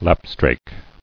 [lap·strake]